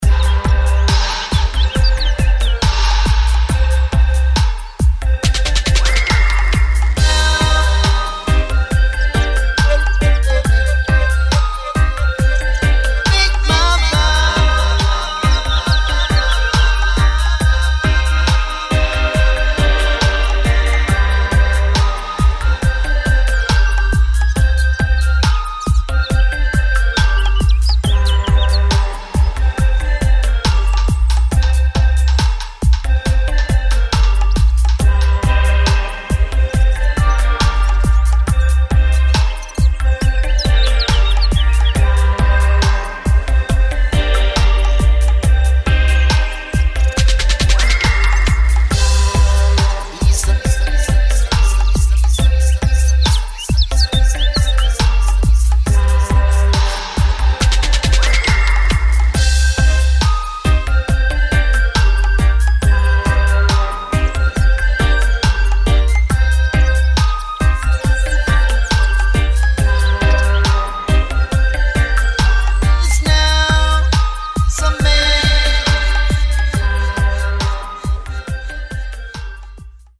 [ DUB / REGGAE / DUBWISE ]